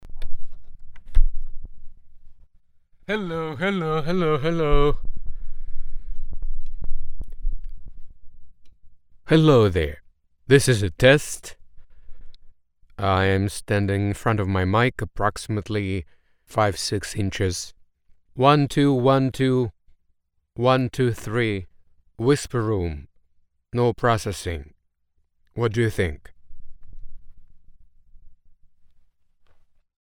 I have a whisperroom booth that I use for singing and voiceover.
I was told that there are some FQs around ~300 and ~450 hz that need to be toned down by 2 and 3 db.
I attached the voice I recorded so you can hear.
Test whisperrom no processing.mp3
There is some serious low end rumble in places.
It's a very dead acoustic, if that is what you are looking for.